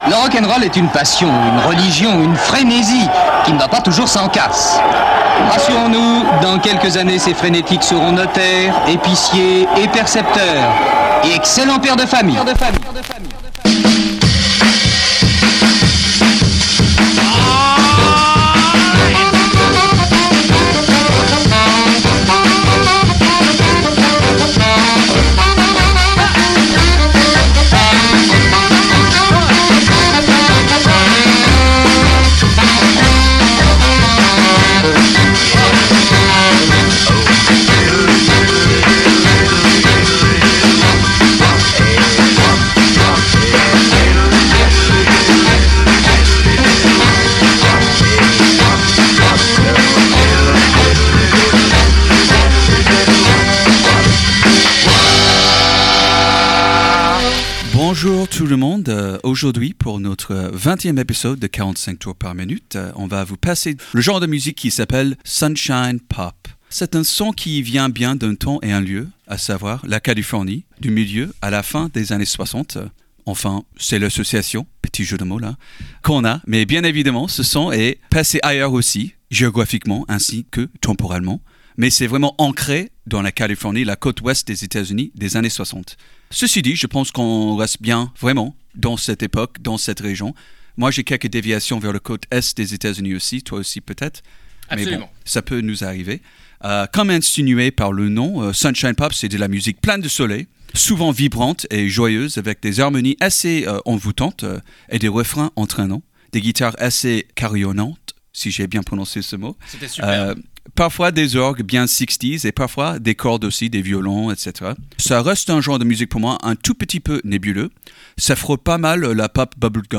#20 - Sunshine Pop
☀ La fin de l'hiver semble être pour bientôt, alors écoutons de la sunshine pop. ☀